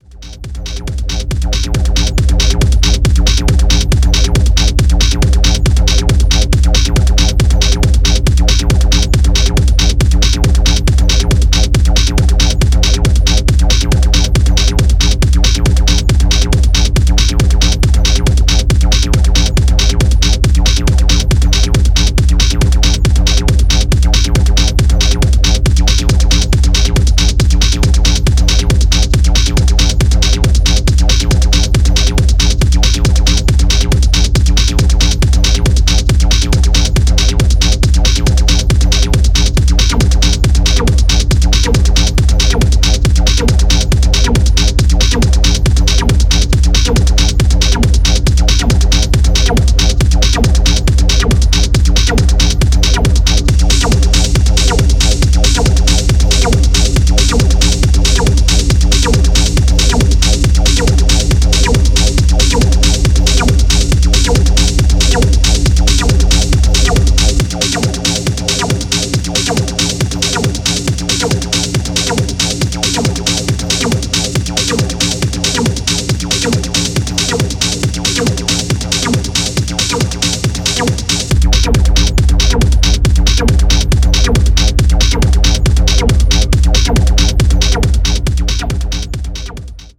Styl: Techno